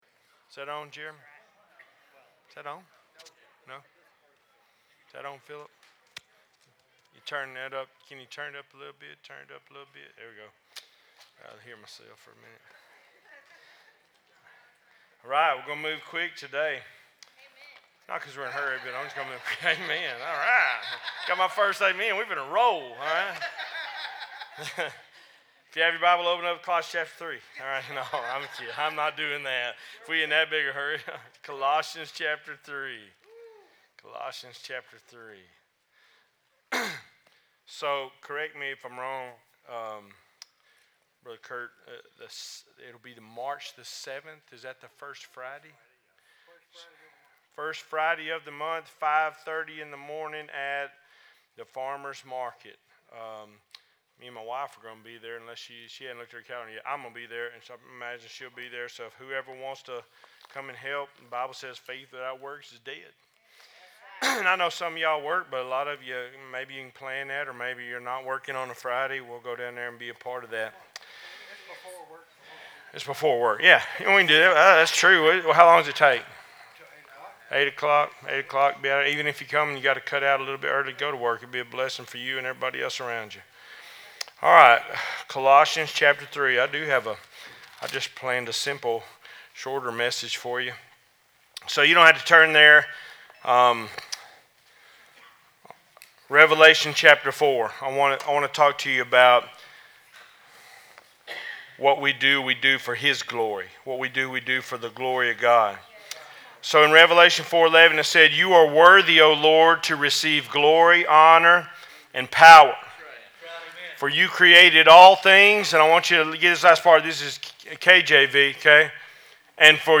11-17-24 Cedar Creek Missionary Baptist Church Sermons podcast